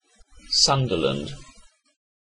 Sunderland (/ˈsʌndərlənd/
En-uk-Sunderland.ogg.mp3